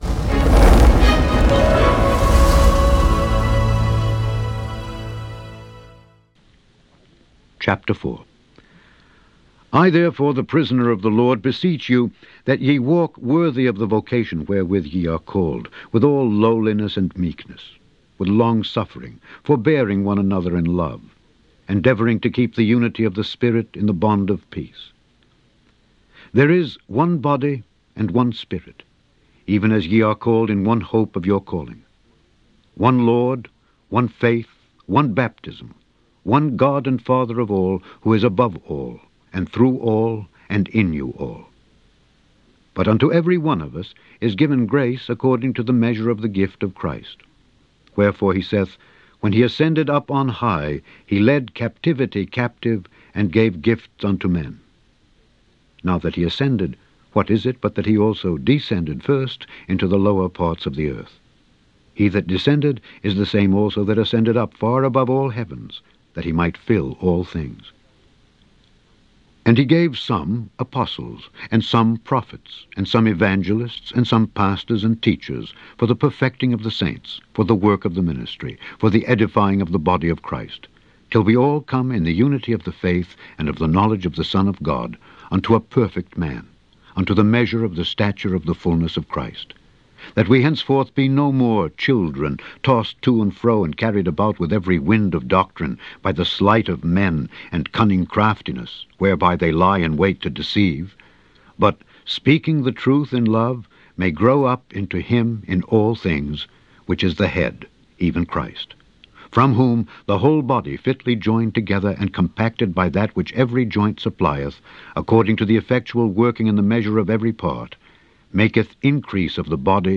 Daily Bible Reading: Ephesians 4-6